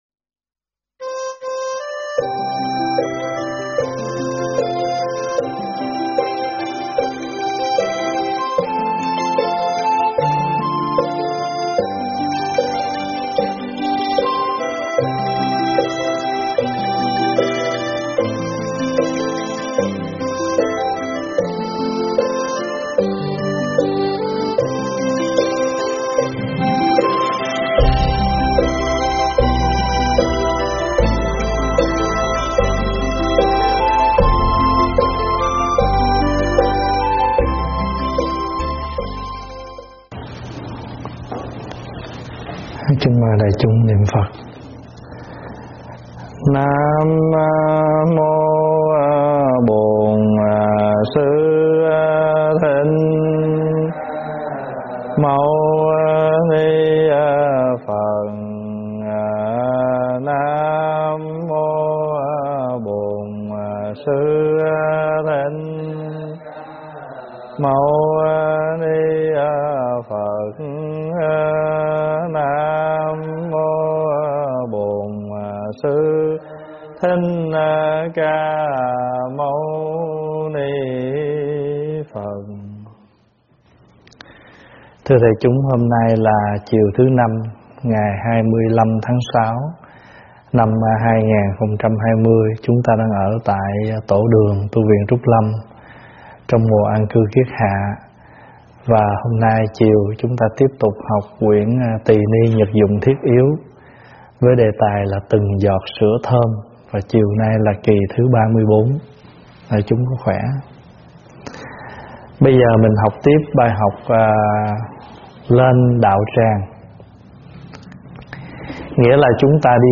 Mời quý phật tử nghe mp3 thuyết pháp Từng Giọt Sữa Thơm 34
giảng tại Tv Trúc Lâm, Ngày 25 tháng 6 năm 2020